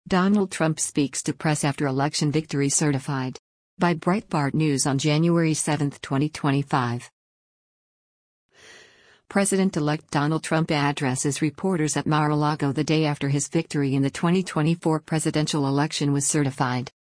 President-elect Donald Trump addresses reporters at Mar-a-Lago the day after his victory in the 2024 presidential election was certified.